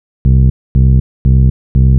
TSNRG2 Off Bass 011.wav